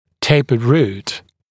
[‘teɪpəd ruːt][‘тэйпэд ру:т]конусообразный корень, расширяющийся корень